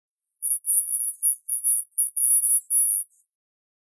I was able to hear “zzz” sounds like the buzzing of a bee, according to the rhythm or intonation of the speech.
You have a “carrier wave” (in this case, a 10000 Hz (10 kHz) sine wave), and then make the amplitude of the carrier wave follow the amplitude of the “message” (the amplitude of the carrier wave is “modulated” by the amplitude of the message).
Because you can only hear the “shape” of the words, “ball” and “fall” and “row” and “cow” will all sound virtually identical when encoded.